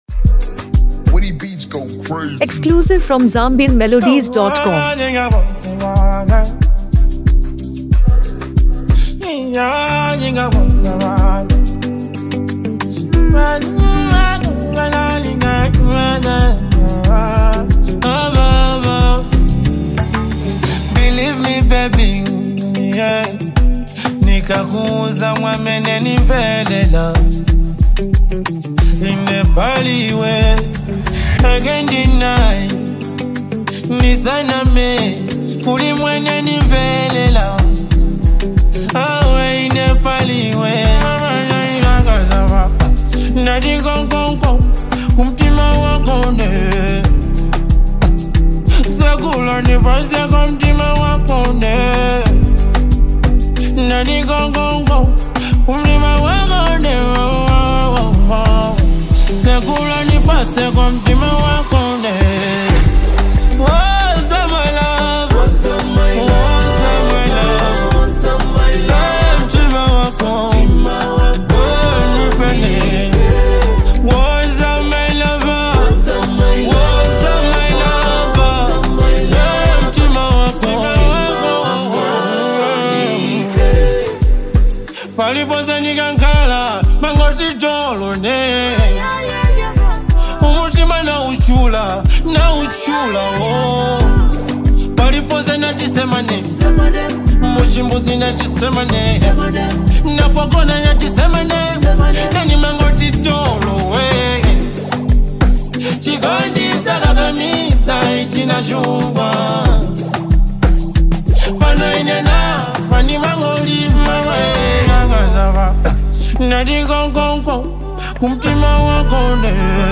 Genre: Afro-beats